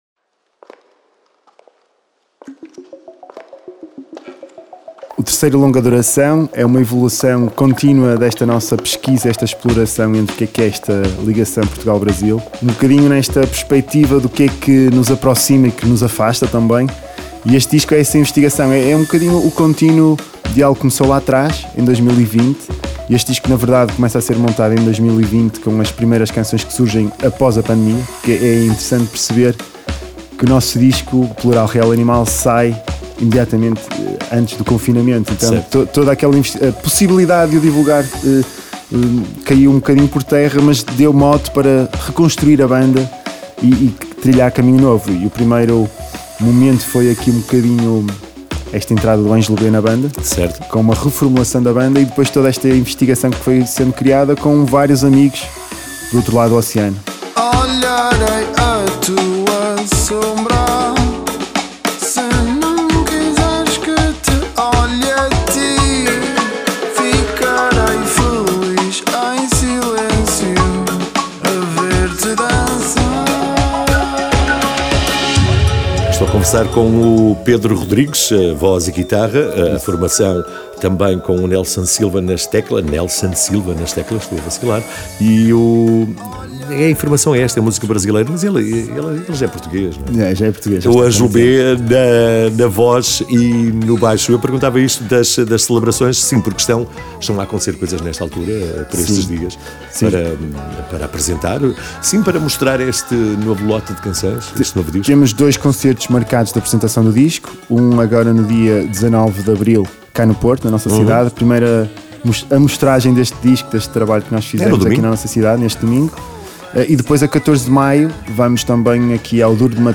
Entrevista Holy Nothing